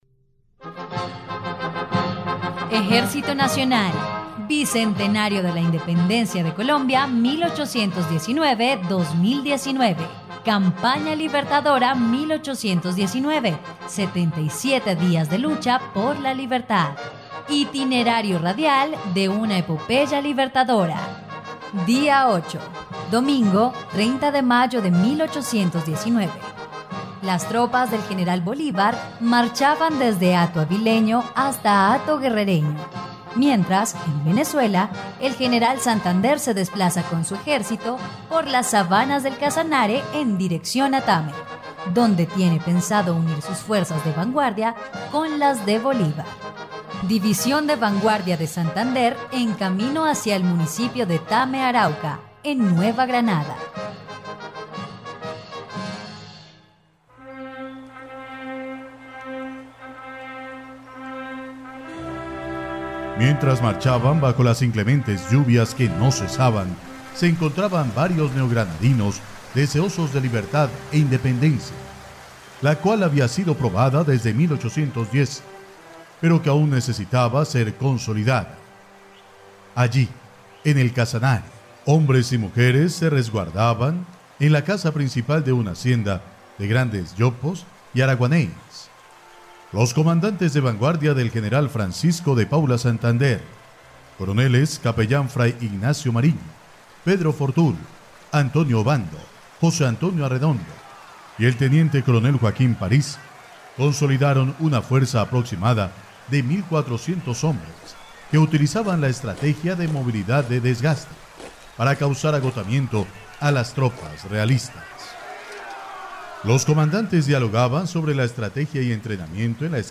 dia_08_radionovela_campana_libertadora.mp3